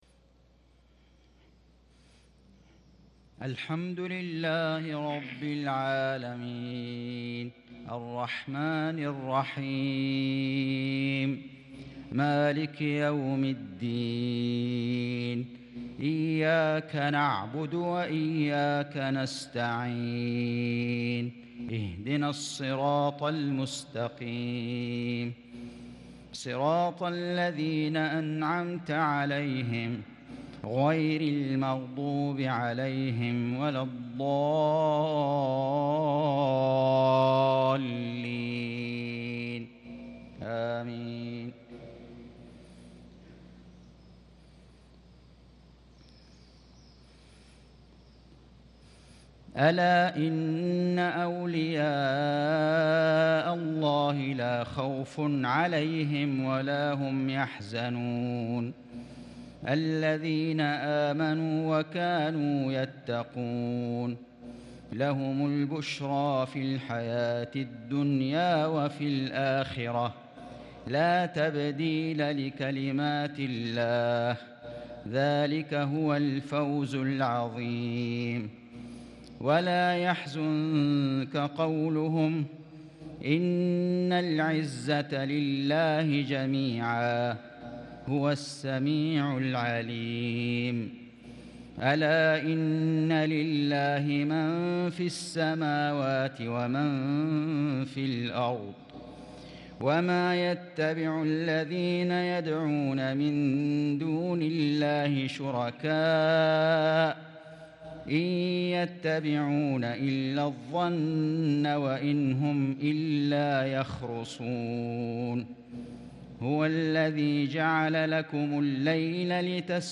مغرب ٥ جمادى الثاني ١٤٤٣هـ | سورة يونس | Maghrib prayer from Surah Yunus 8-1-2022 > 1443 🕋 > الفروض - تلاوات الحرمين